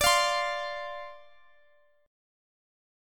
Listen to Dm7 strummed